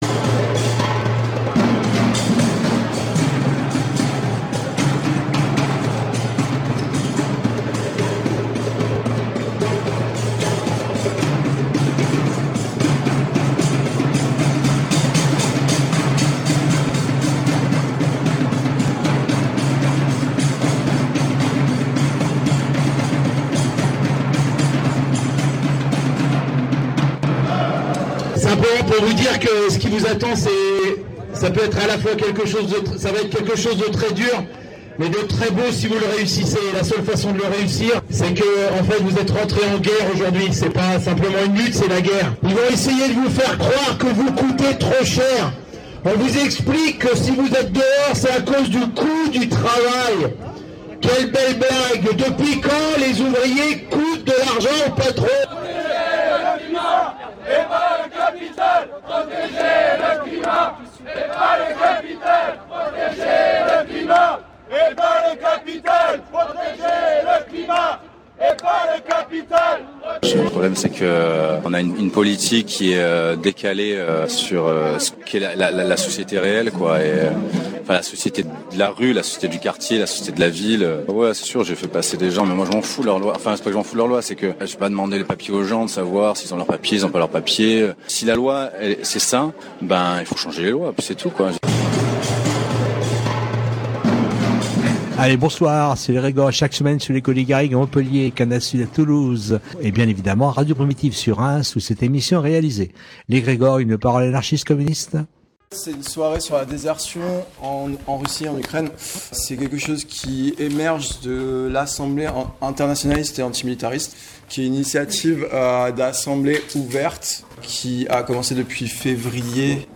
Samedi 3 mai à la Maison Ouverte s’est tenue une discussion autour de la désertion et le refus de la guerre en Ukraine et Russie avec l’Initiative Olga Taratuta (bulletin anti-guerre et initiative de solidarité avec les déserteurs lancé aux premiers jours du conflit). C’est une grande partie de cette discussion que nous vous proposons dans l’émission de ce jour. L’idée de cette rencontre fut d’offrir une mise en contexte du déroulement du conflit et des positions qui ont pu y être tenues, en mettant en lumière le choix de la désertion et du refus de la guerre.